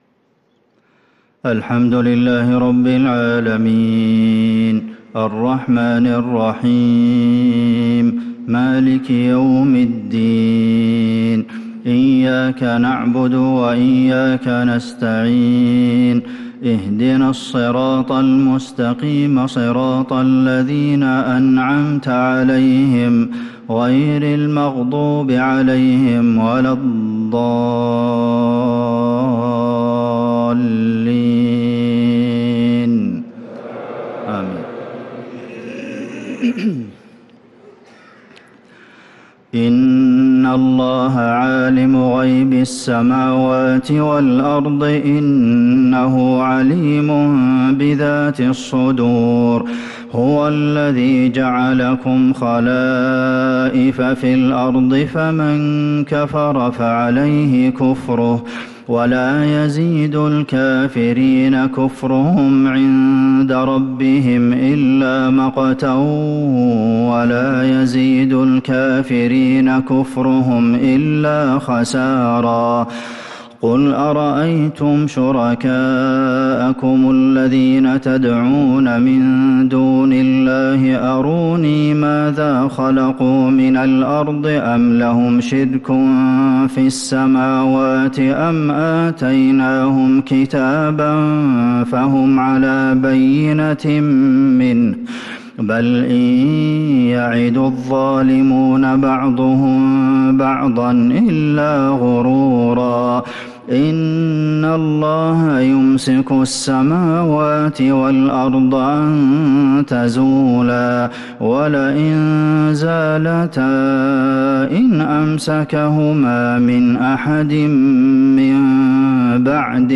صلاة العشاء ٩-٢-١٤٤٧هـ | خواتيم سورة فاطر 38-45 | Isha prayer from Surah Fatir | 3-8-2025 > 1447 🕌 > الفروض - تلاوات الحرمين